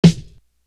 Magnavox Snare.wav